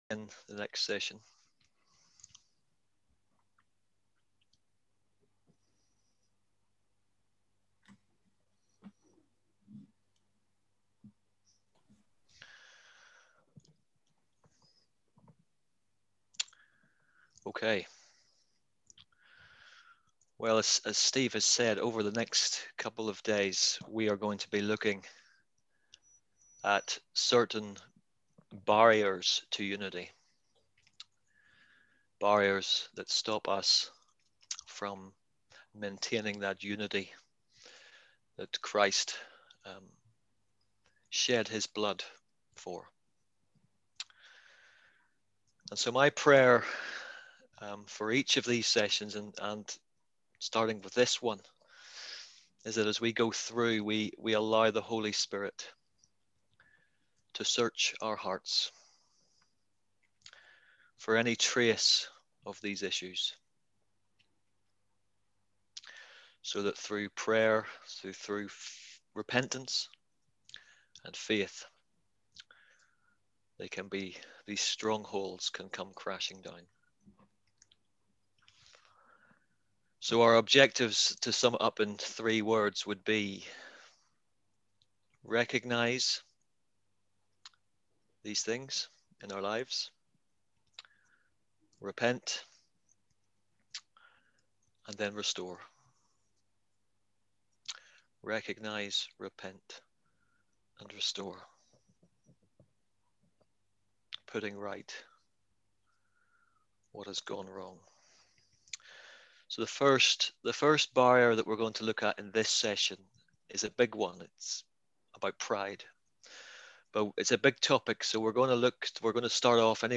When Christians disagree: October 2020: ZOOM